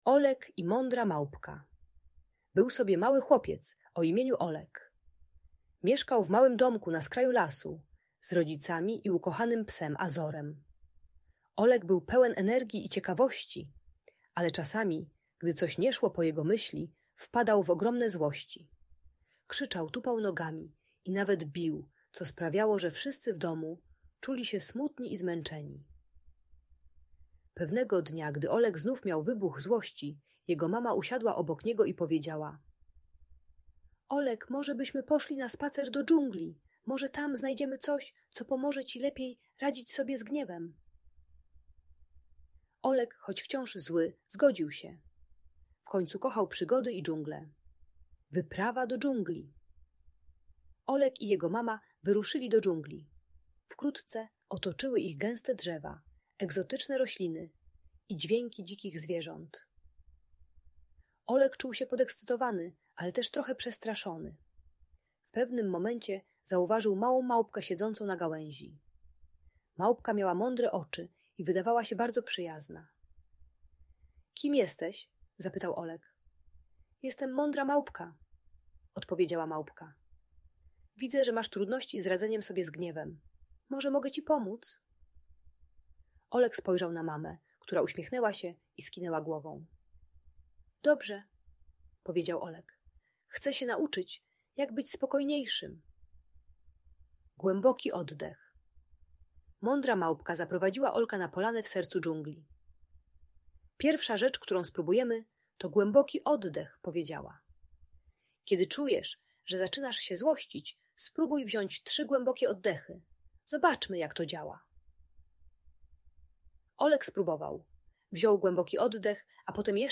Olek i Mądra Małpka - Bajkowa Opowieść o Gniewie - Audiobajka